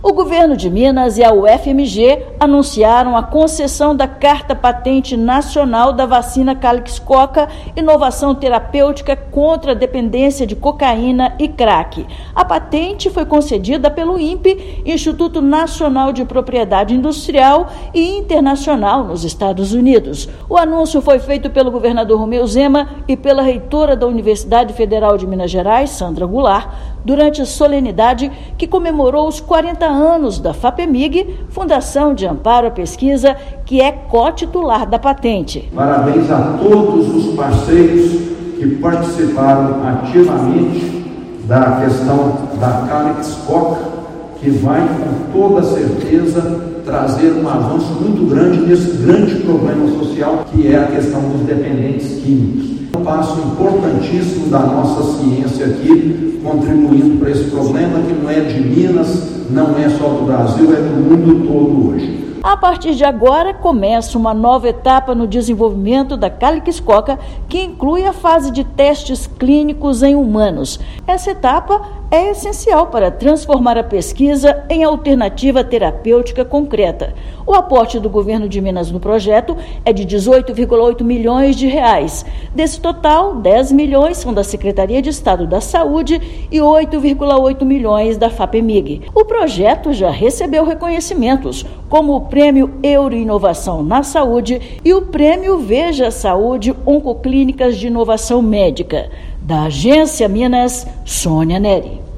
Avanço foi apresentado durante a celebração dos 40 anos da Fapemig, que reuniu pesquisadores, gestores e autoridades em Belo Horizonte. Ouça matéria de rádio.